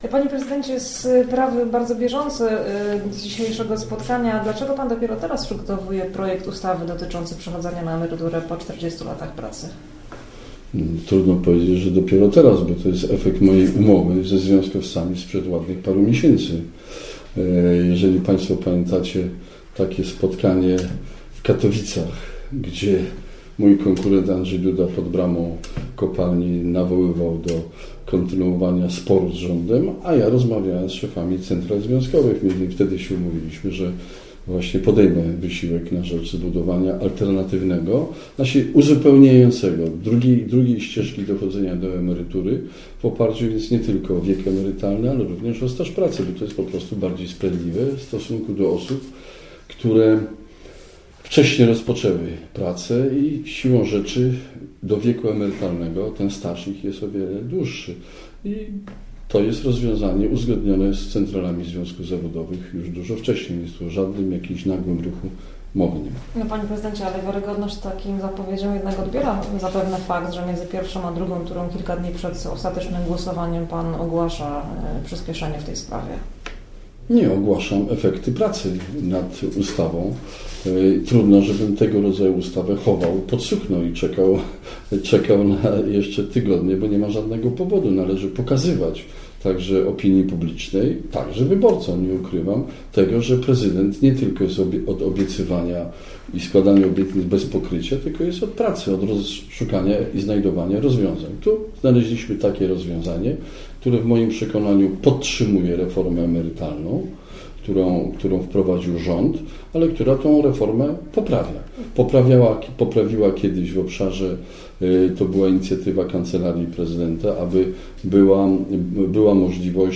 Być może zamiast dwóch debat kandydatów do fotela prezydenckiego będa trzy. W wywiadzie dla Radia Merkury prezydent Bronisław Komorowski pytany o to czy w piątek wieczorem w Lubinie dojdzie do debaty z Andrzejem Dudą z udziałem Pawła Kukiza odpowiedział, że ustalenia trwają.